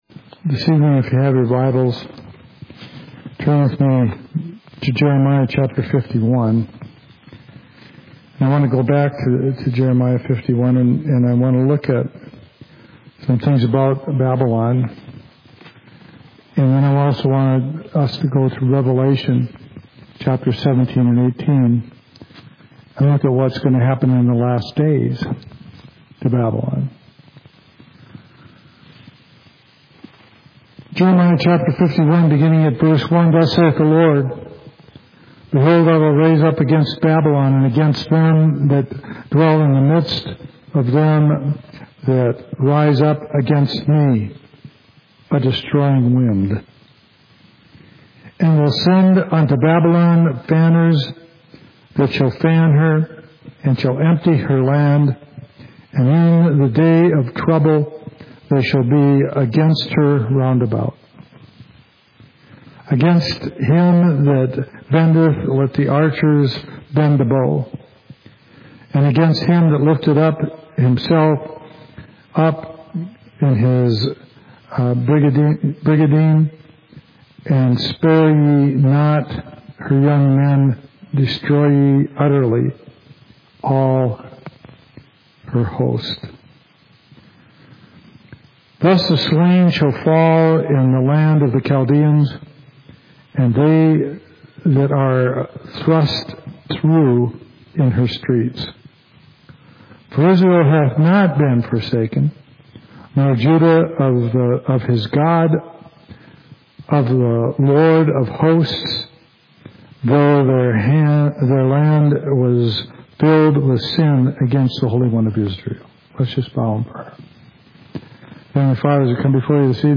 Study in Jeremiah